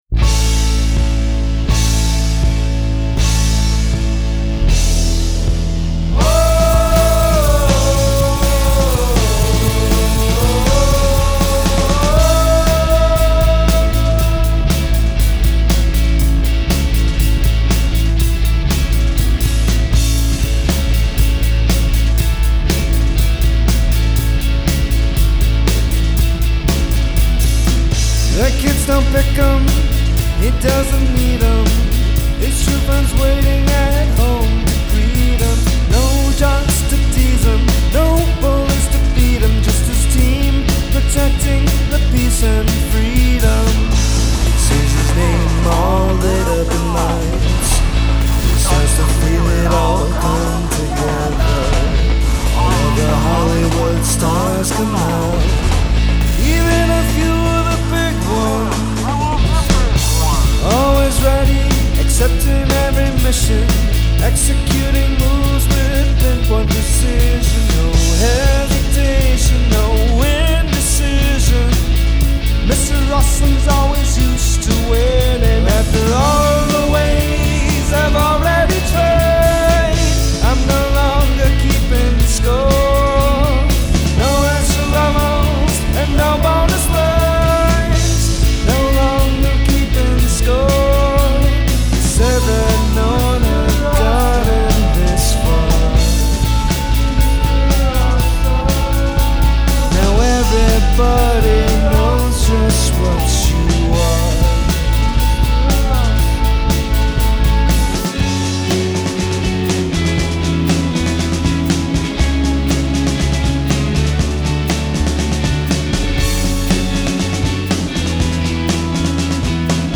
vocals, rhythm guitar, programming
lead guitar, bass, backing vocals, programming
Here’s what the indie-rock band